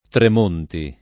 [ trem 1 nti ]